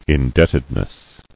[in·debt·ed·ness]